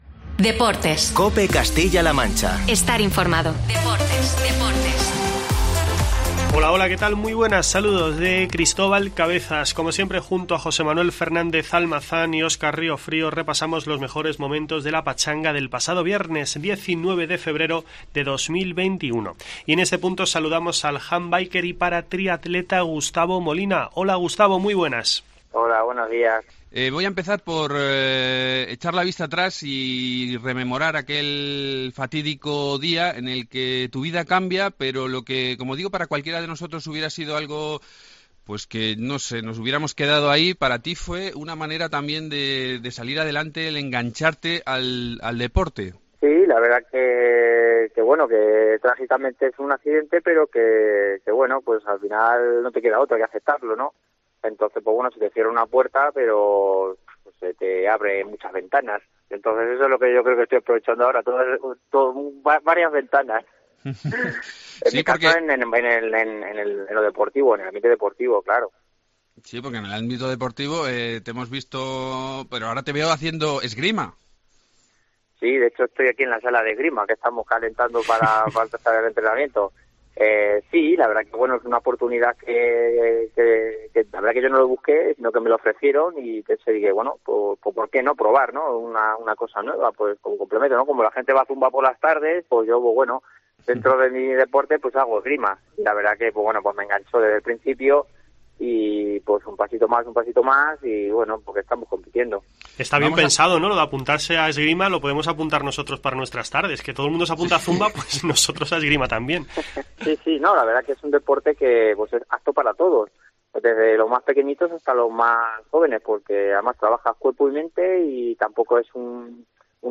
AUDIO: No te pierdas la entrevista con el ‘handbiker’ y paratriatleta ciudadrealeño